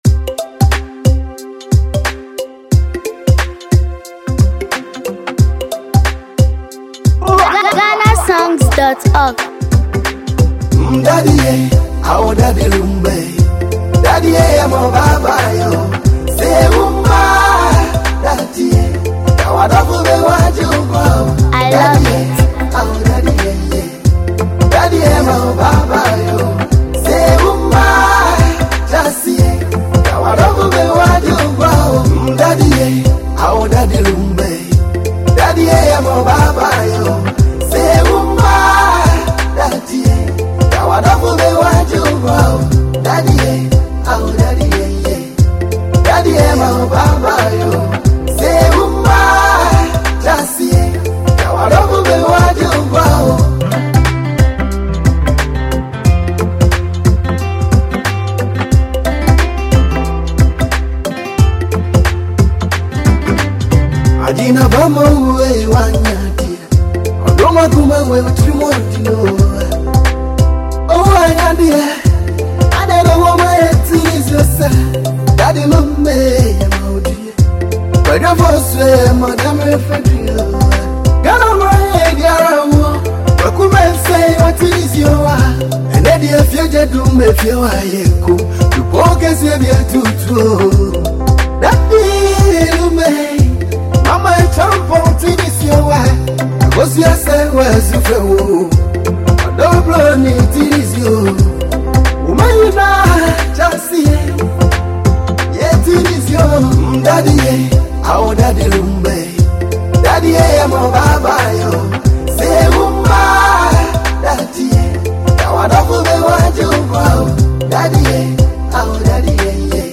Ghanaian singer
smooth highlife melodies
• Clean guitar riffs
• Sweet harmonies
• Calm percussion
• A soul-touching chorus
• Beautiful highlife vibe